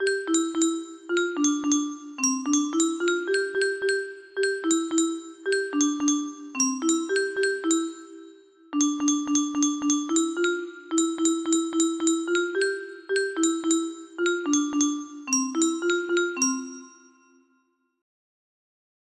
02Lightly Row Paper music box melody